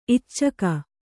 ♪ iccaka